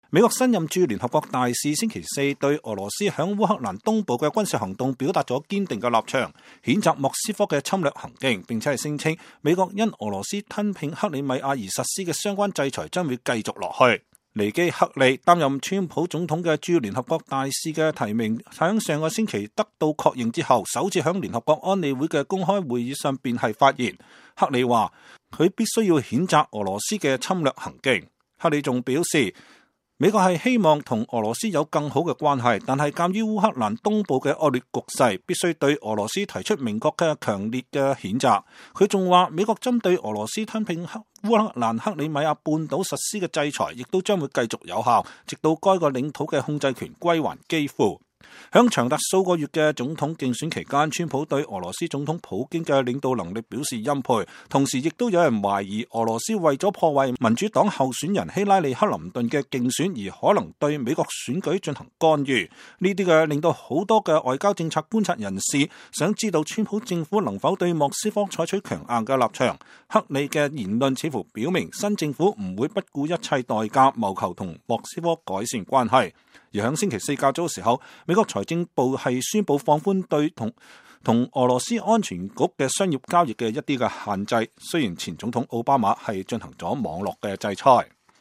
２０１７年２月２日，美國新任駐聯合國大使尼基•黑利在安理會會議上首次發言。